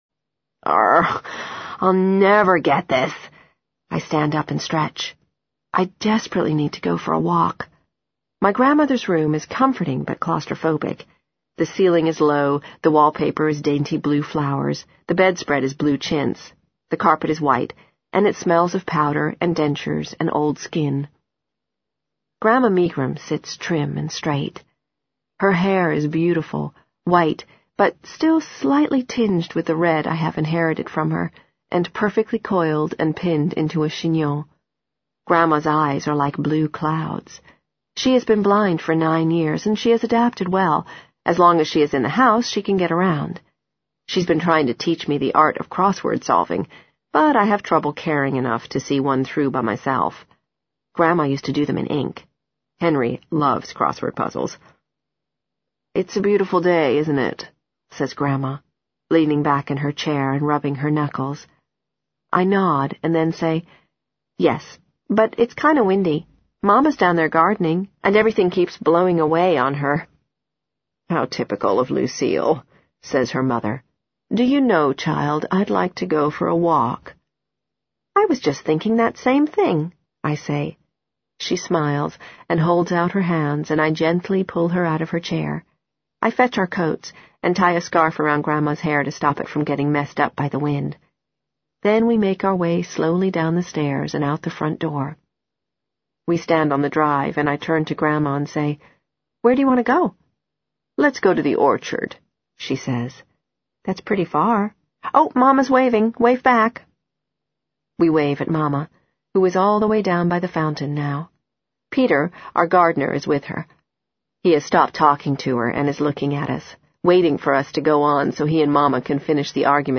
在线英语听力室【时间旅行者的妻子】102的听力文件下载,时间旅行者的妻子—双语有声读物—英语听力—听力教程—在线英语听力室